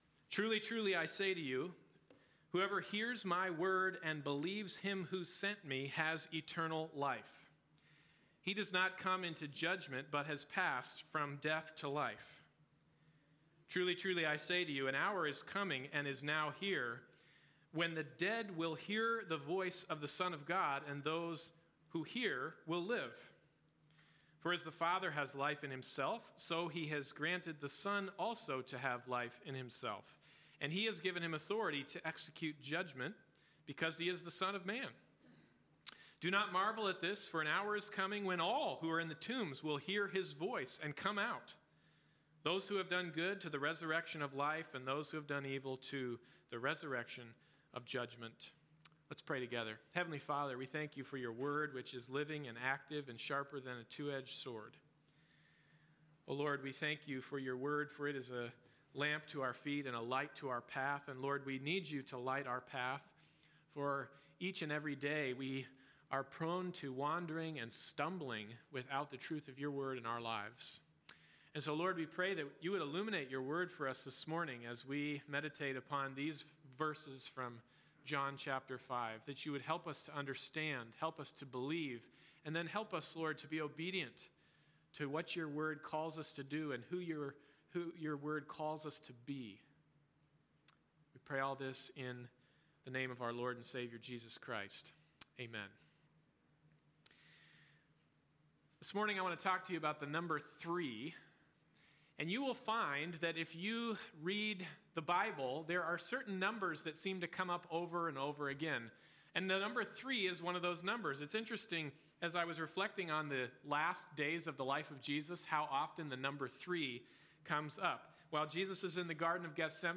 Holy Week Passage: John 5:24-29 Service Type: Sunday Morning Service « Change Your Clothes The Lord’s Supper